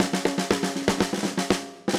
Index of /musicradar/80s-heat-samples/120bpm
AM_MiliSnareA_120-01.wav